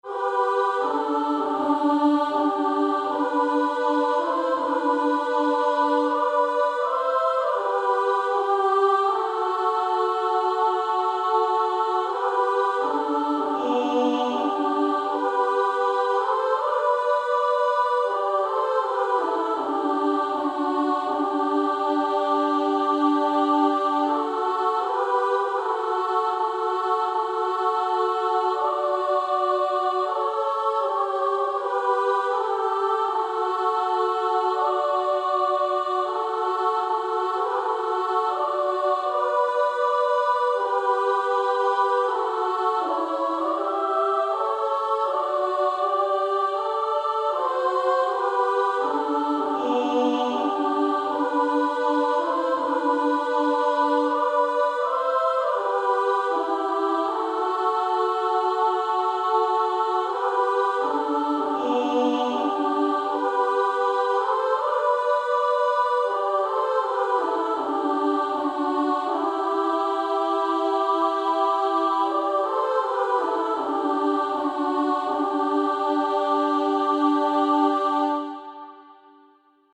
Soprano Track. Alto Track.